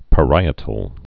(pə-rīĭ-təl)